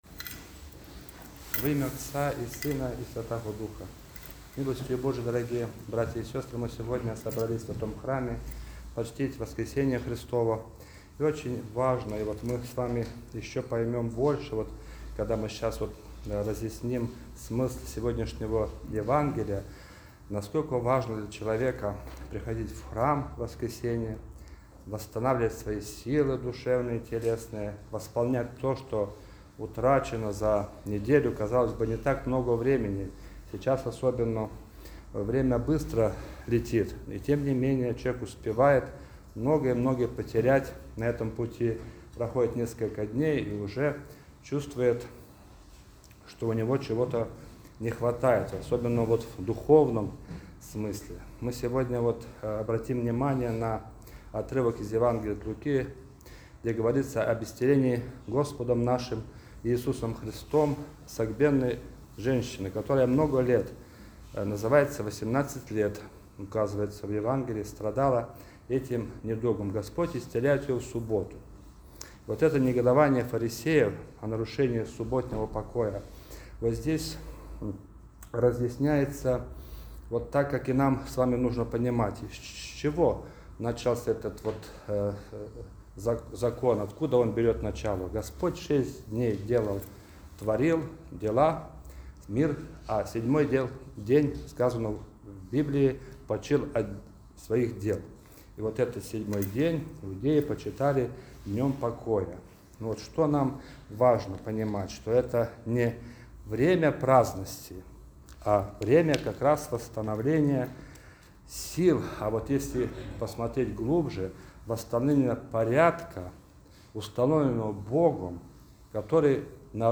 Проповедь-11.12.m4a